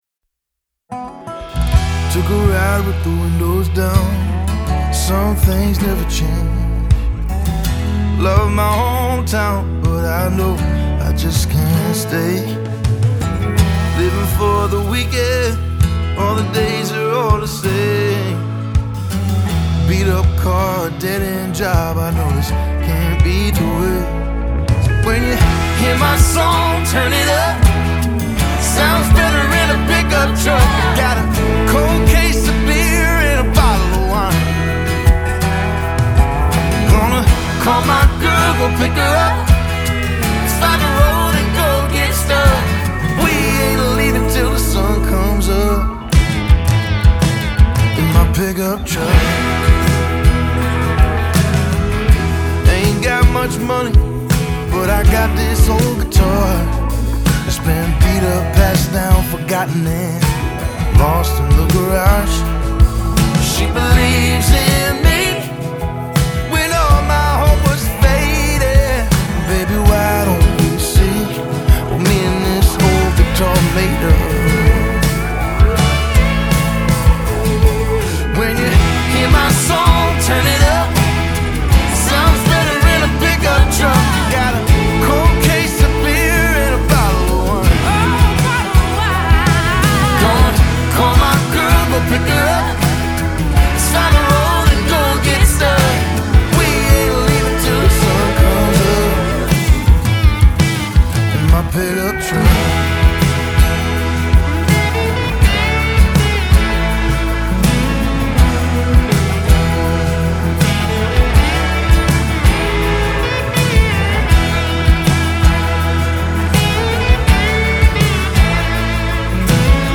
Part crooner, part troubadour, all storyteller.